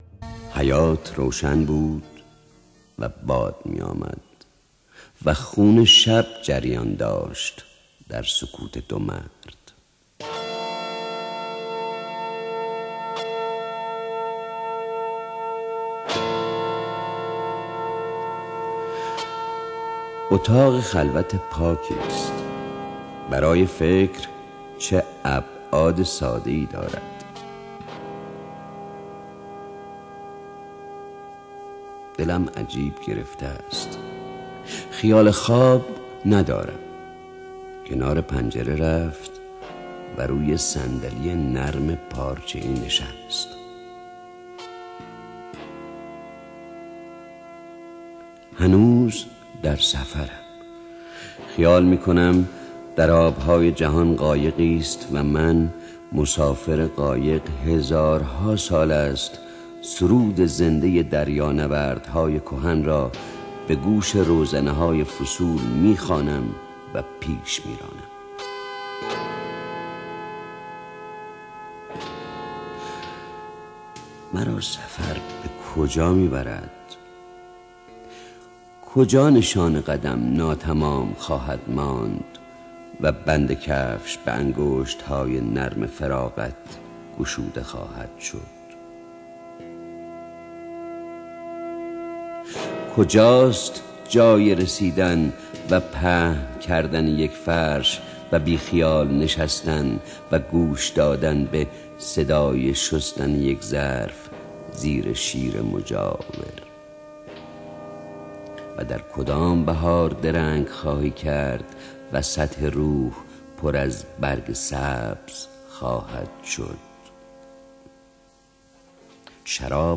دانلود دکلمه مسافر با صدای خسروشکیبایی
گوینده :   [خسـرو شکیبایی]